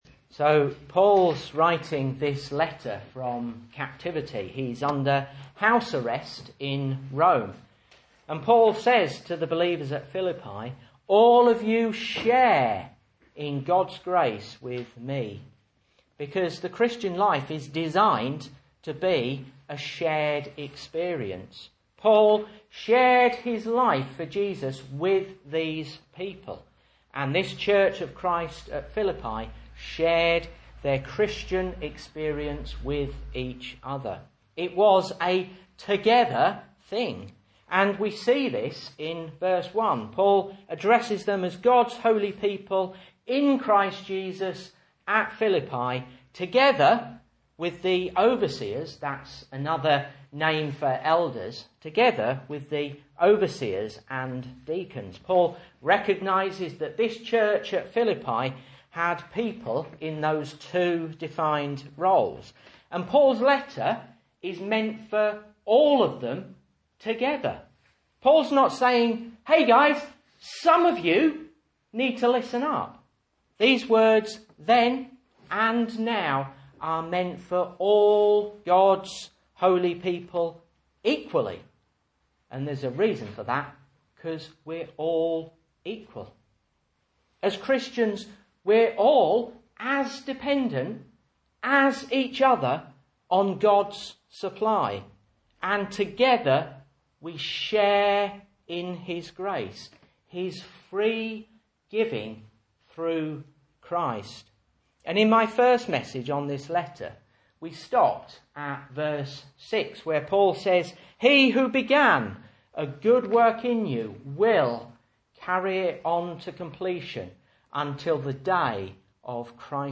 Message Scripture: Philippians 1:7-8 | Listen